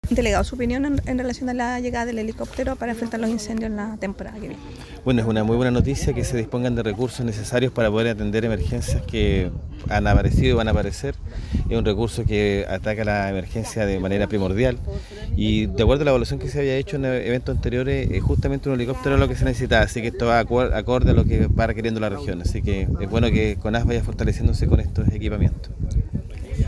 Delegado-Jorge-Alvial.mp3